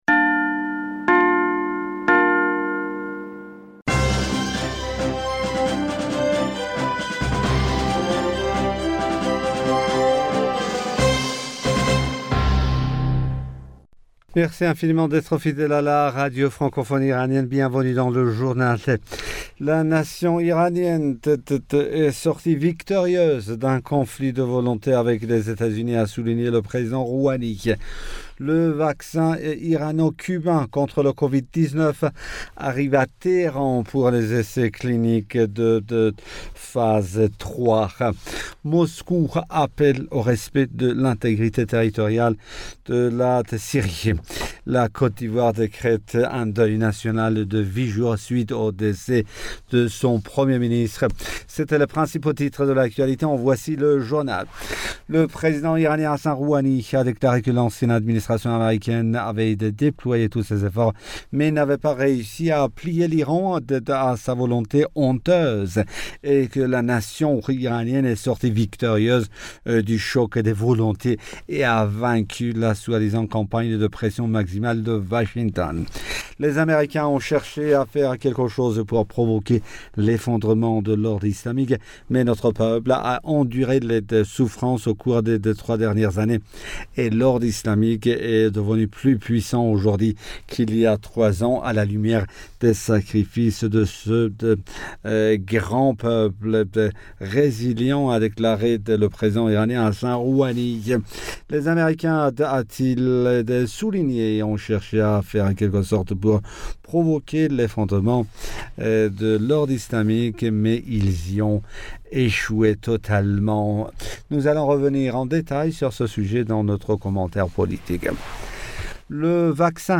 Bulletin d'informationd u 12 Mars 2021